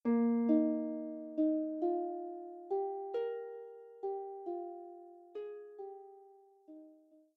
lever or pedal harp